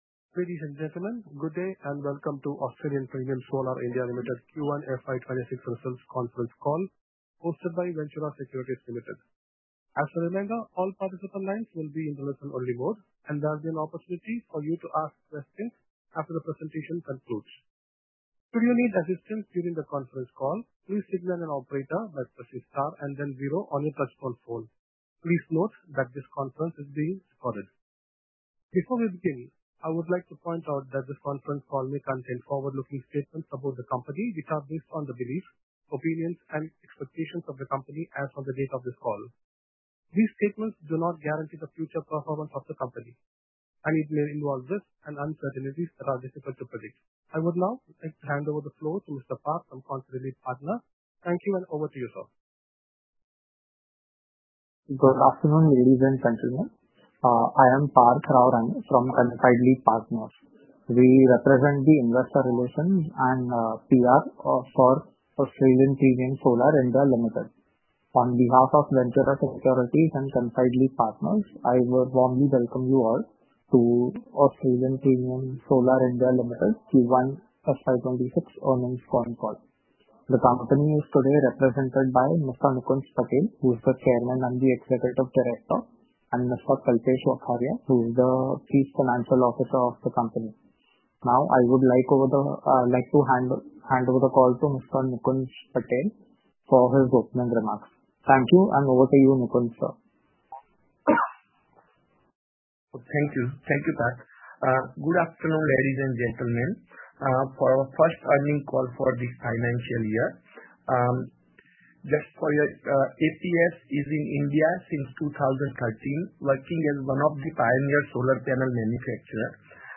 Concalls
Audio-Recording_Q1FY26_Conference-Call.mp3